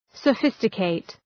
Shkrimi fonetik {sə’fıstə,keıt}